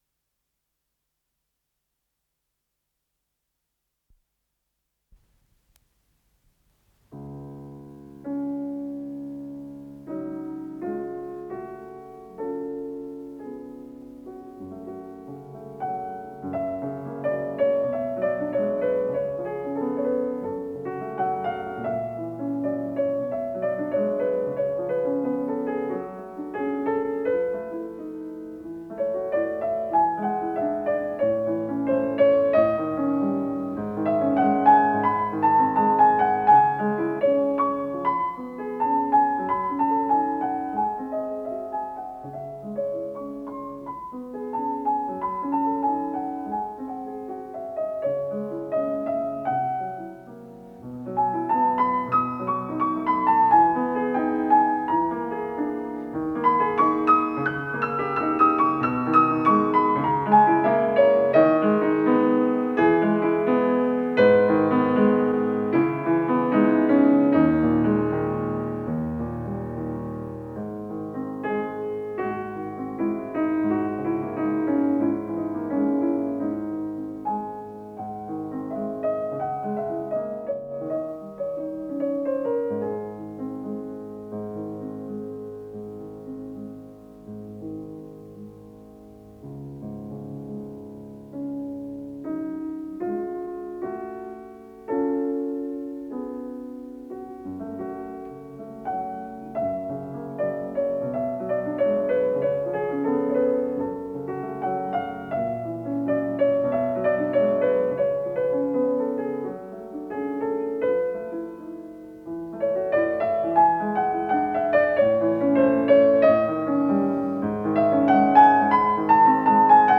с профессиональной магнитной ленты
ПодзаголовокАндантино, фа диез минор
ИсполнителиМихаил Воскресенский - фортепиано
ВариантДубль моно